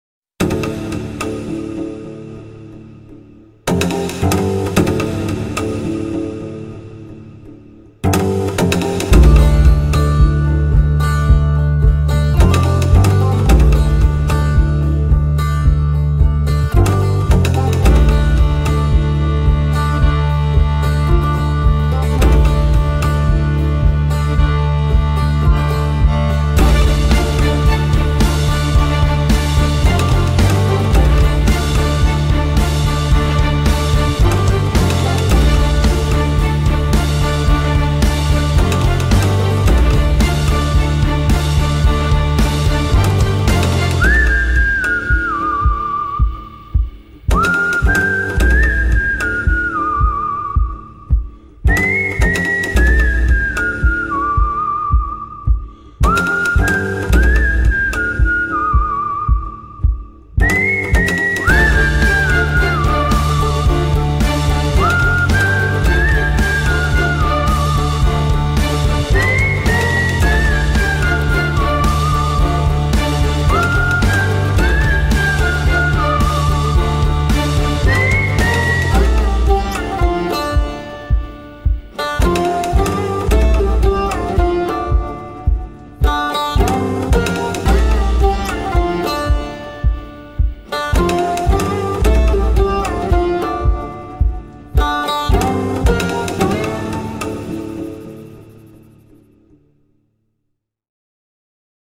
heyecan gerilim aksiyon fon müziği.